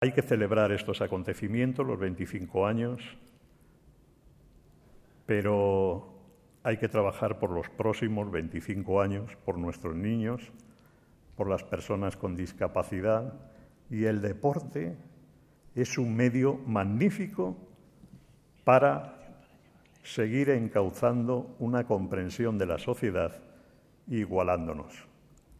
Foto de familia de todos los galardonados junto a los máximos responsables de la ONCE, la FEDC y la presidenta del CSDLa Federación Española de Deportes para Ciegos (FEDCAbre Web externa en ventana nueva) celebró el 27 de septiembre su 25 Aniversario, en una gala en la que se repasó la historia del deporte practicado por las personas ciegas y en la que se rindió homenaje a todas las personas y empresas que han hecho posible alcanzar este cuarto de siglo de existencia y de éxitos competitivos.